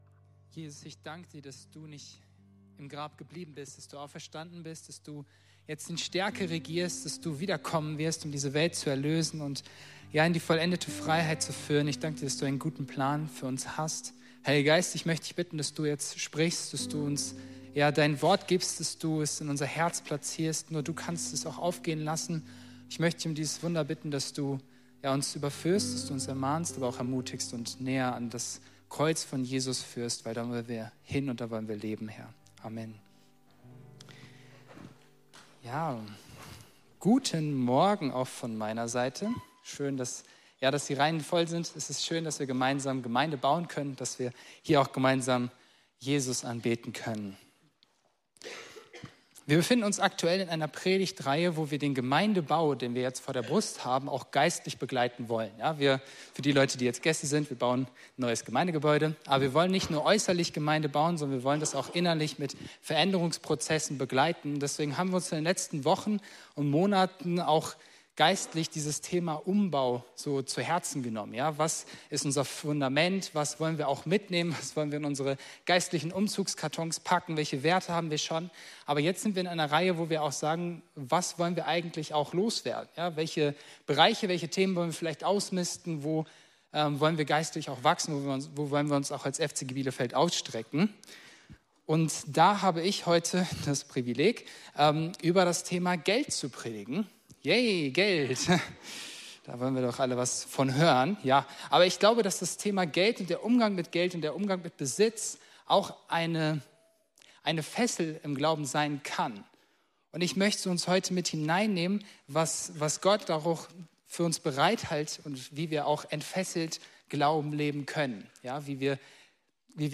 Predigten | Freie Christengemeinde Bielefeld